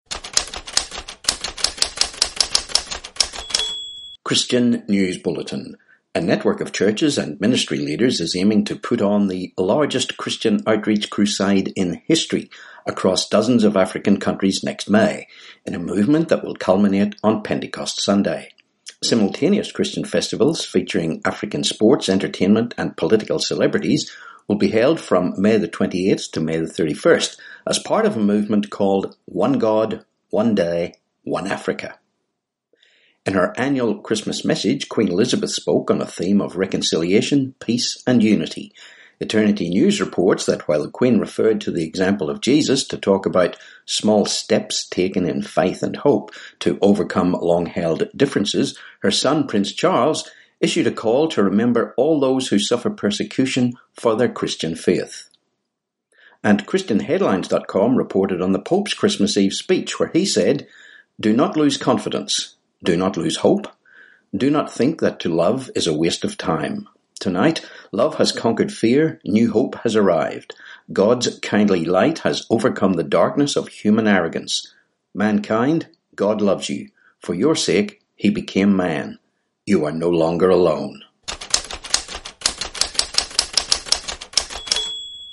This post has an audio player with the news